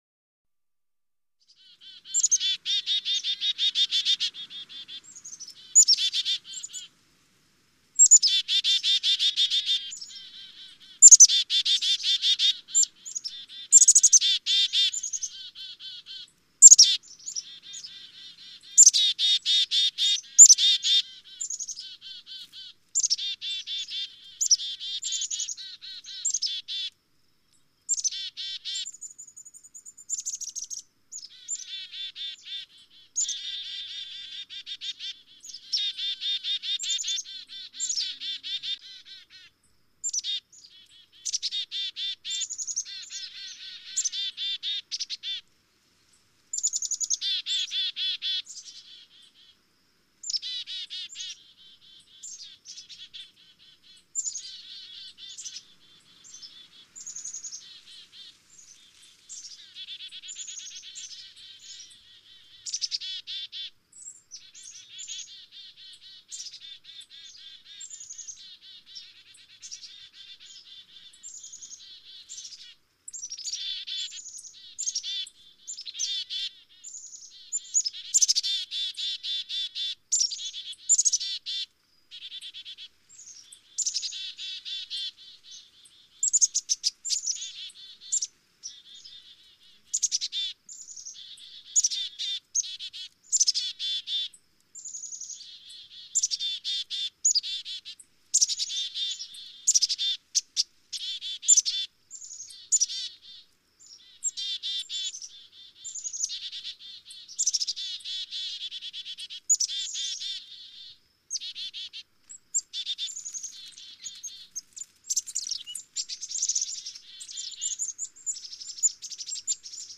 Chickadee, Black-capped Tweets. Thin, High-pitched Tweets With Similar Birds Chirping In The Background. Medium Perspective.